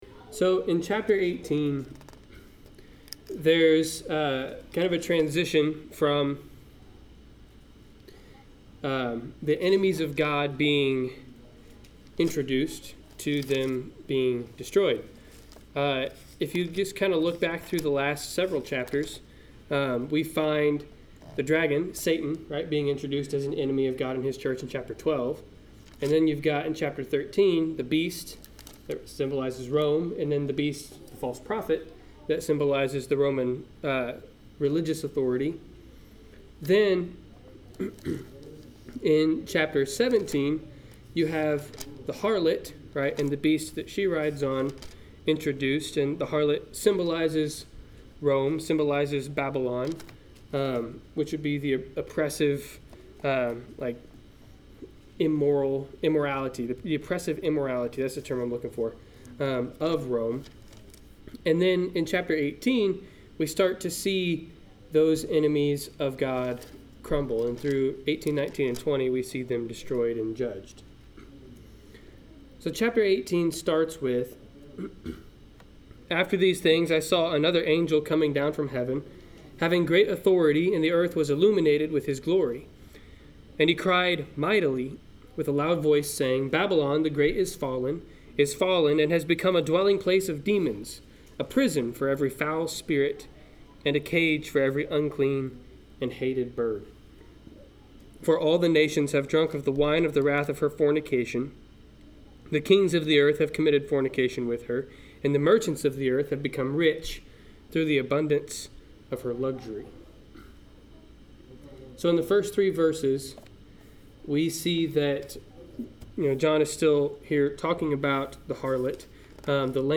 Service Type: Wednesday Night Class